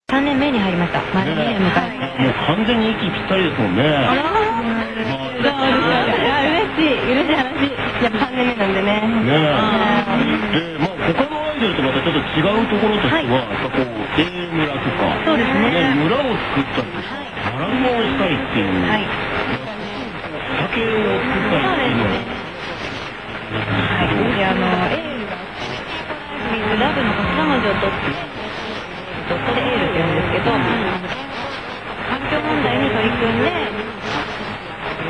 他スレ（もはや他ですね？）にでてきたAeLL. のAM ラジオ放送の件。
↓雑音がひどいので後でファイル削除するかも。
>>43の放送で、「AeLL．といえばグラビアの印象が強いのですが、、」との進行役パーソナリティの発言がありました。